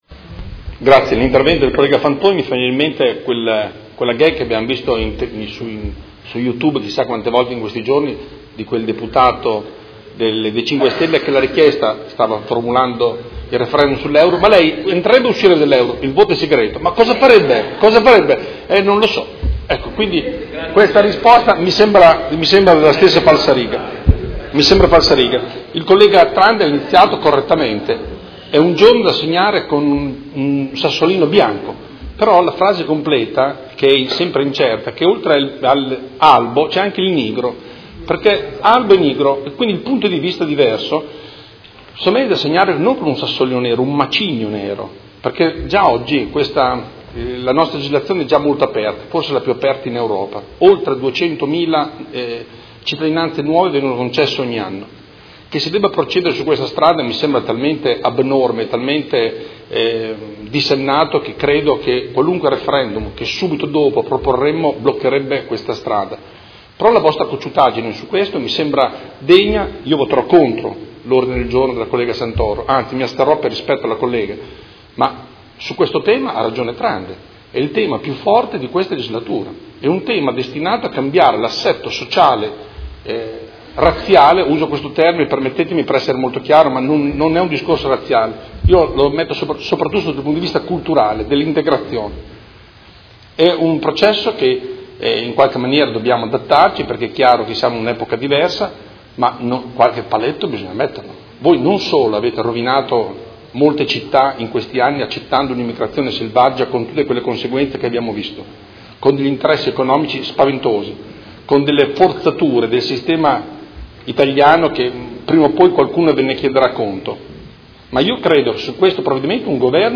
Seduta del 14/12/2017 Dibattito.